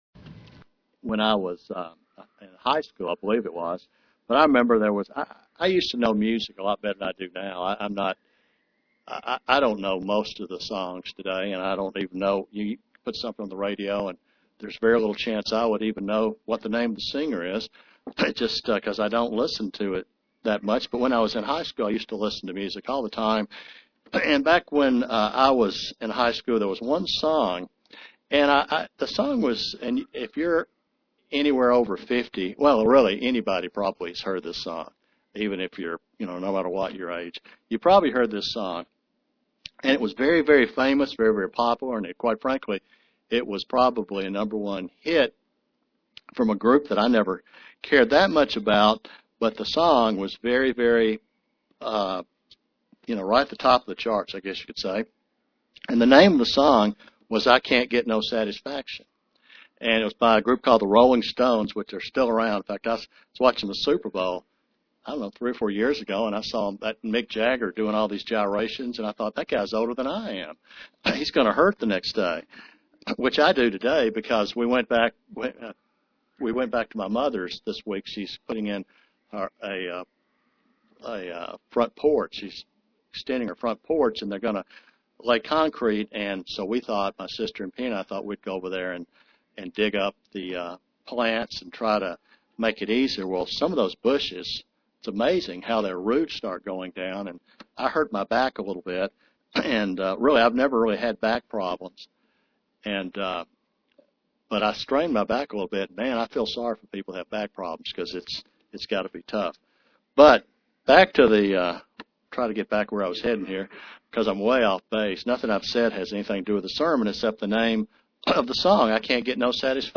Print Being Content is a Godly characteristic UCG Sermon Studying the bible?